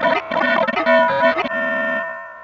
16 Harsh Realm Guitar Tone 1.wav